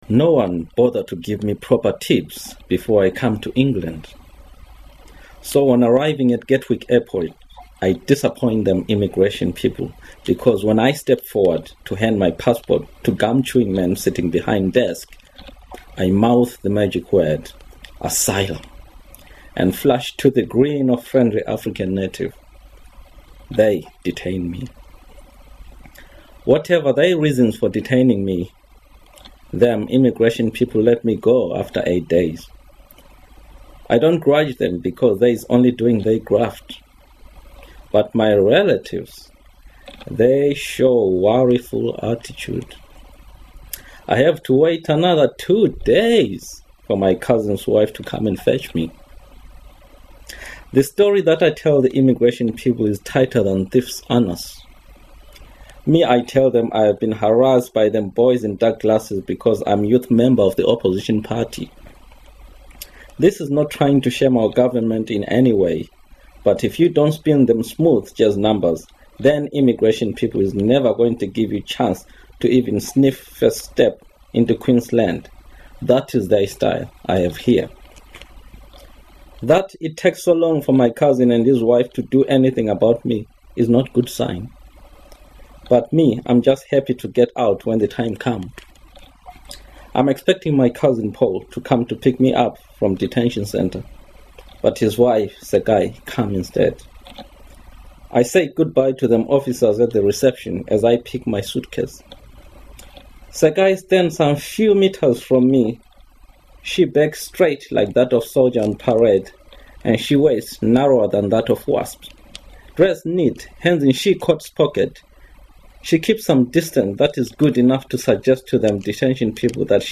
Harare North - Reading by the author
Listen to Chikwava read from the first few pages of this novel.